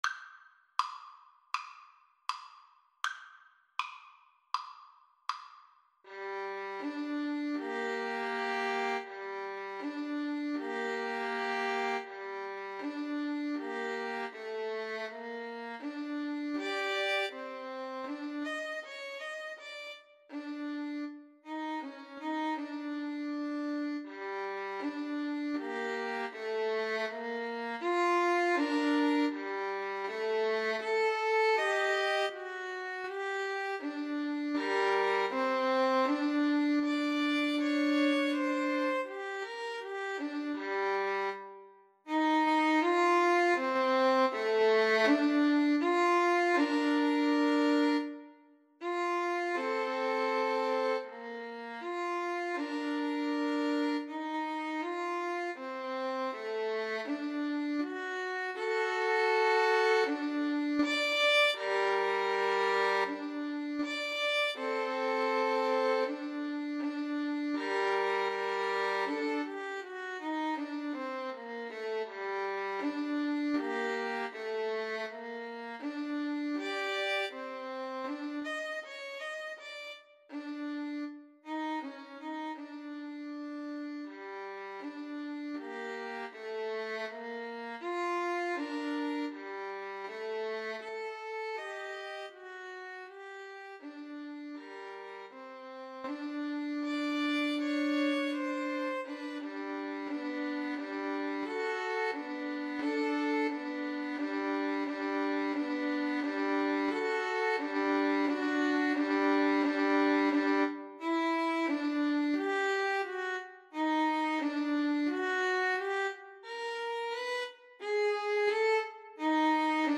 4/4 (View more 4/4 Music)
Andante cantabile
Classical (View more Classical Violin Duet Music)